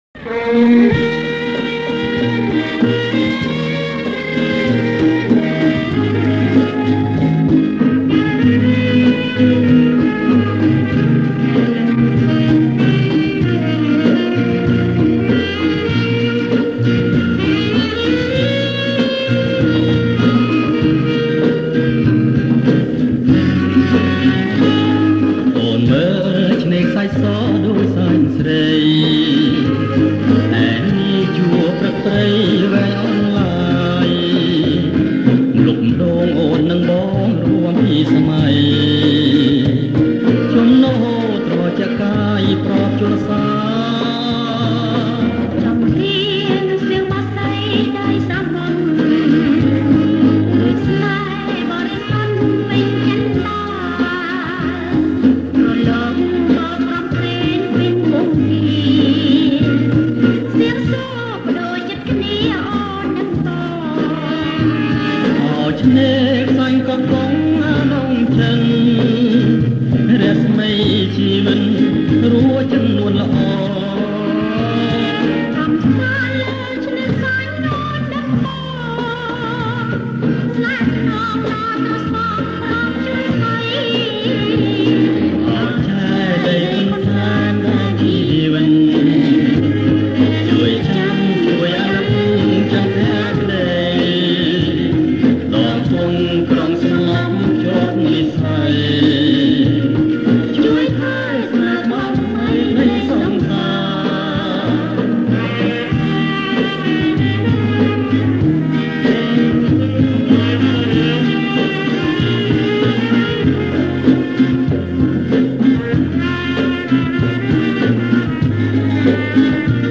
ប្រគុំជាចង្វាក់ BOLERO